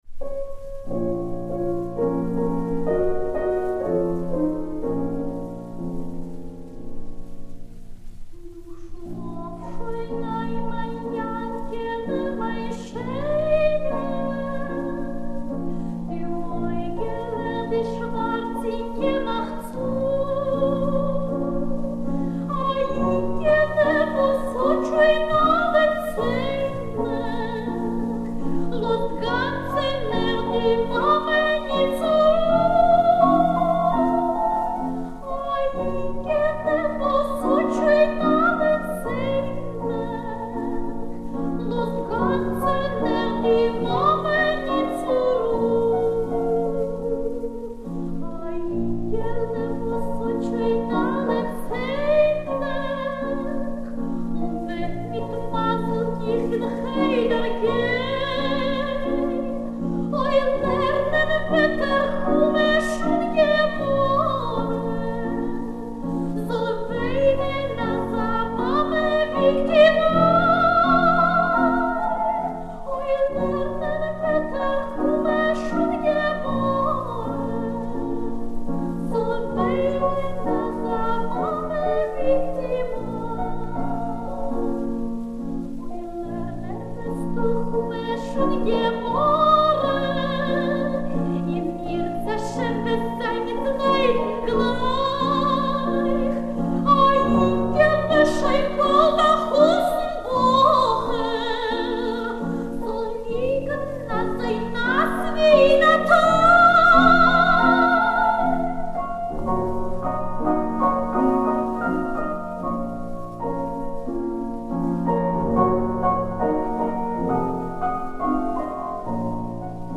Jewish cradle songs, 2nd part
The words and melody of a cradle song aim to soothe and calm the child in order for him to sleep well.